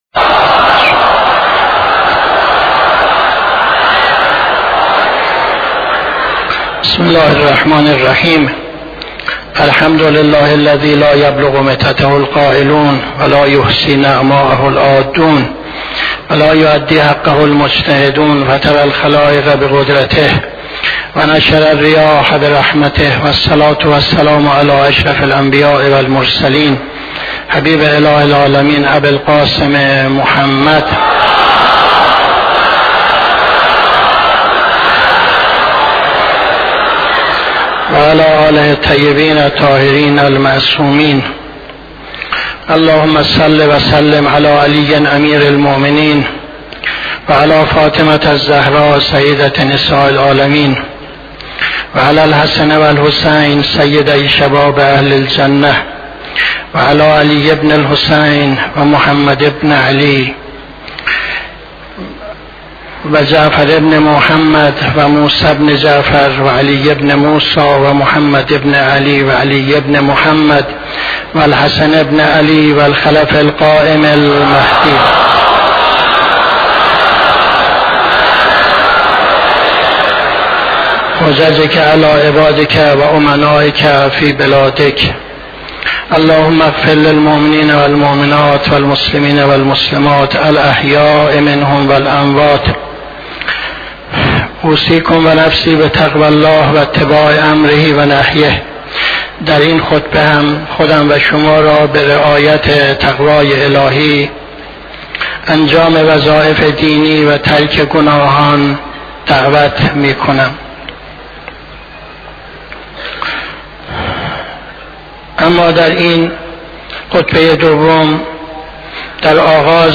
خطبه دوم نماز جمعه 02-11-77